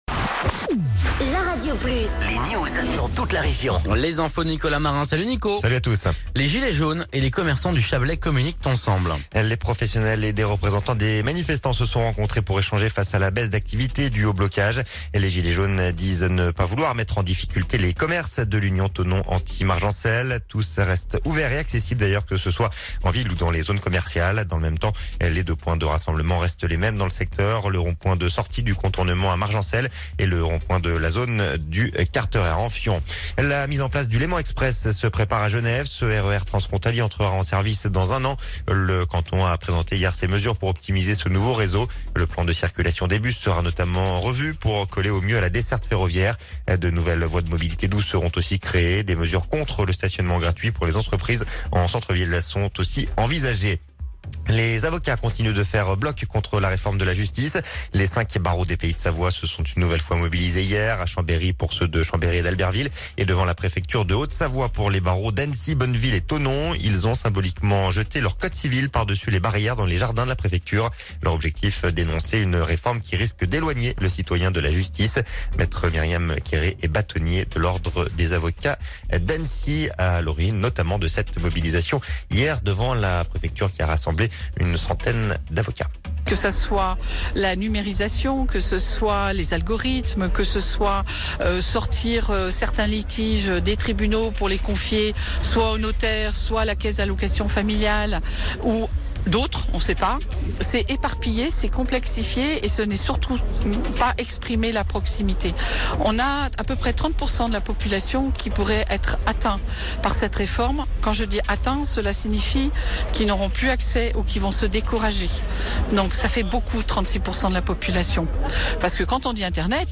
Reportage sur les animations de Noël à Thonon